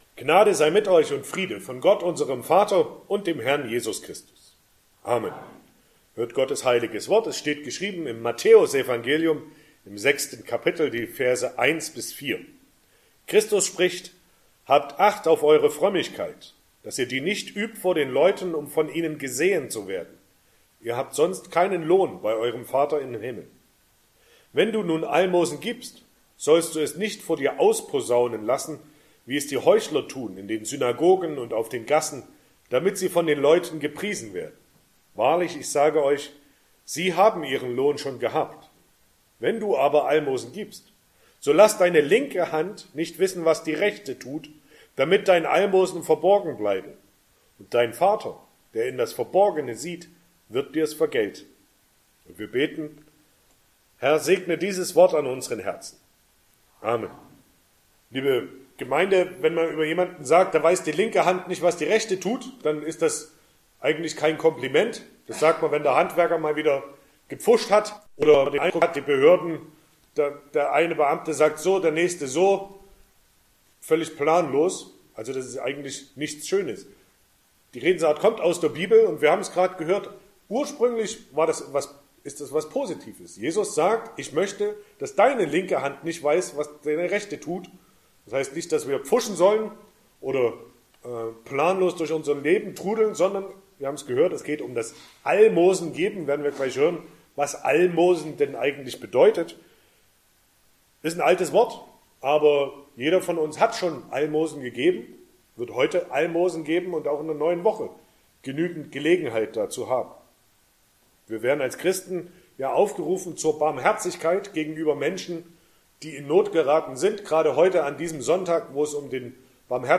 Sonntag nach Trinitatis Passage: Matthäus 6, 1-4 Verkündigungsart: Predigt « 12.